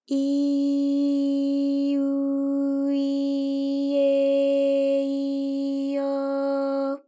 ALYS-DB-001-JPN - First Japanese UTAU vocal library of ALYS.
i_i_u_i_e_i_o.wav